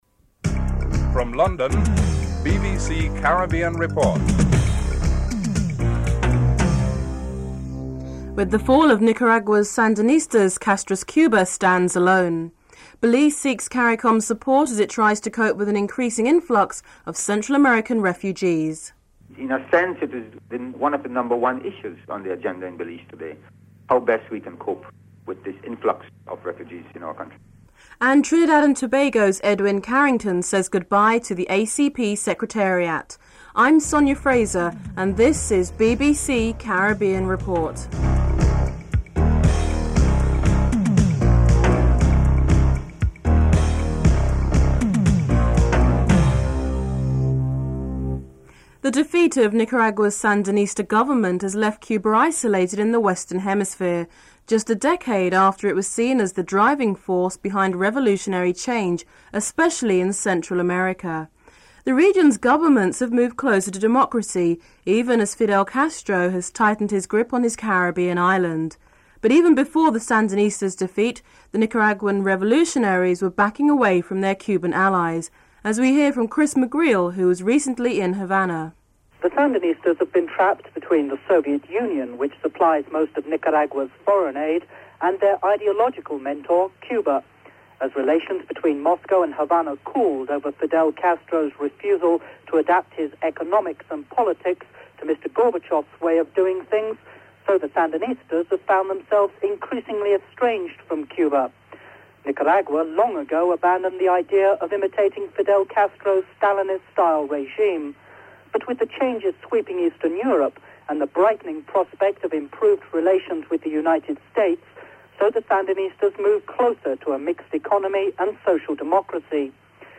Includes musical interlude at the beginning of the report.